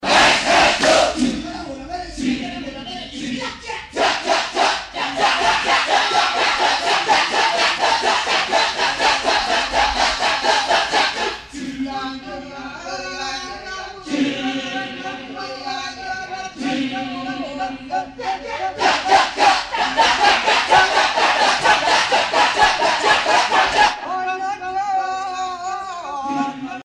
Le son du ketjak
Pièce musicale éditée